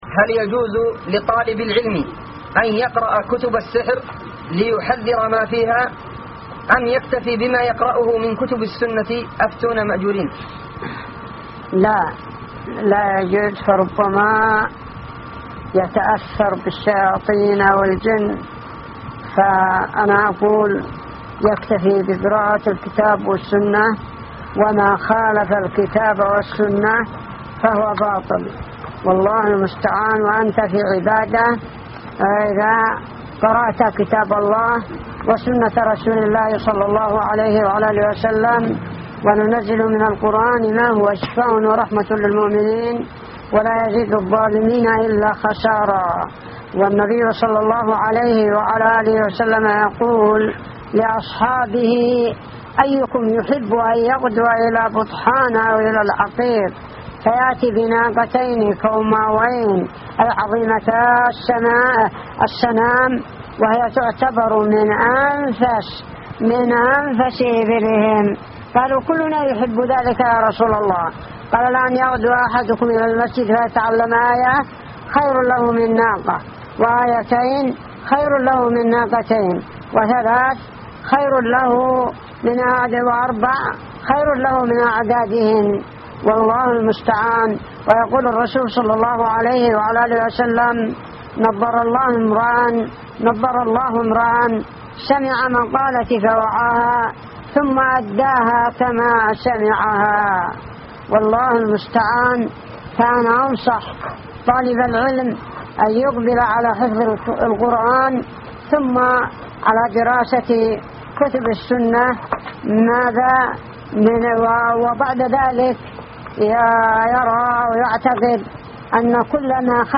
----------- من شريط : ( جلسة بالحرازات ) .